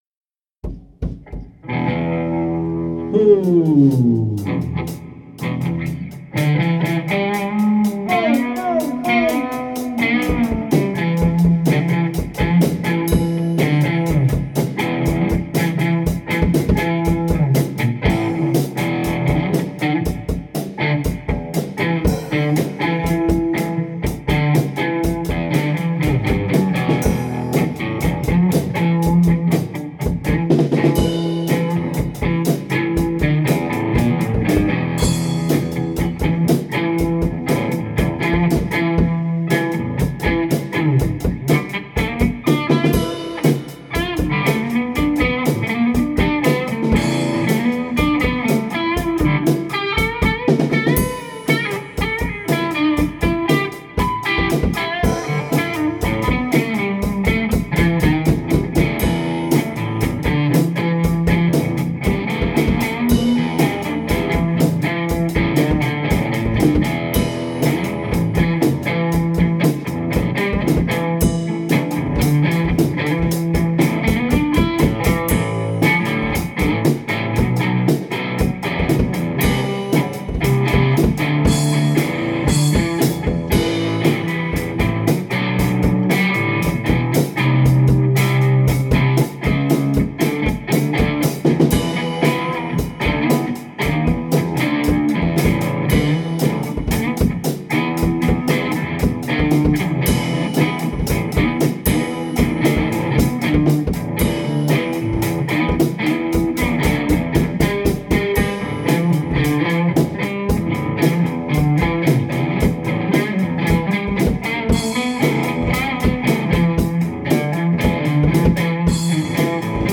where: Marsonic
Jam